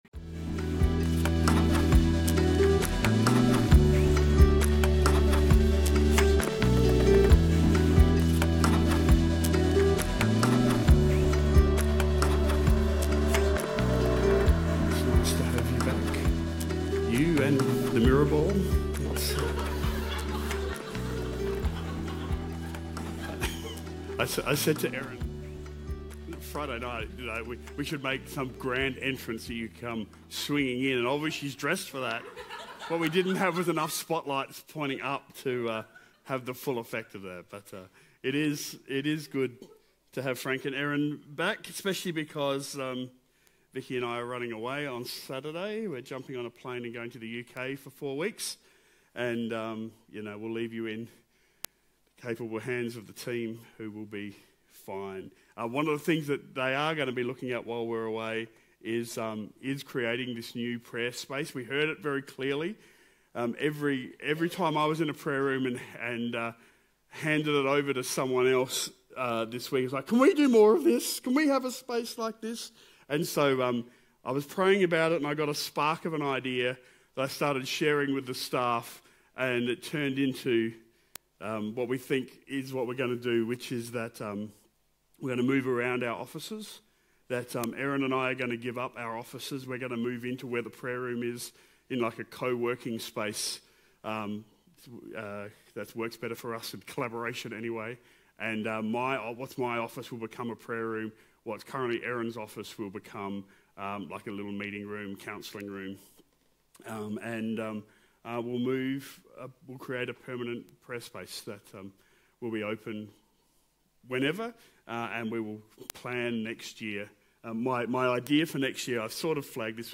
Enfield Baptist Church